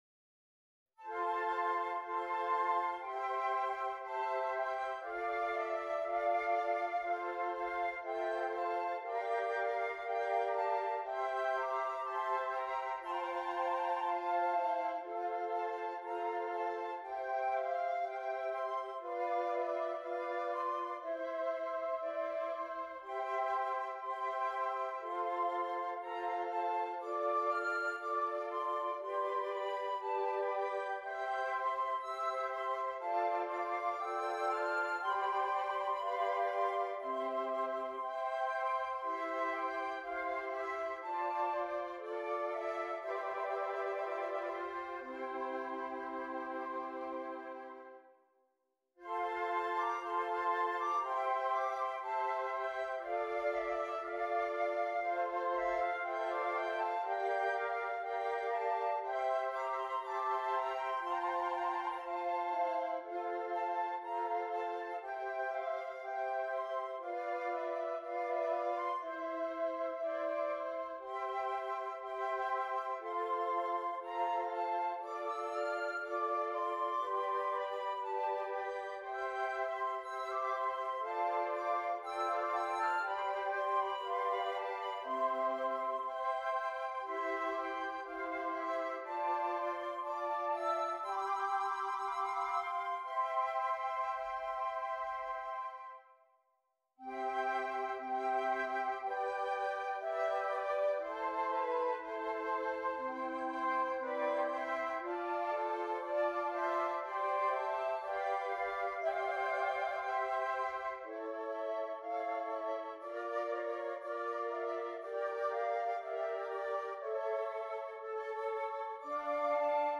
Woodwind
6 Flutes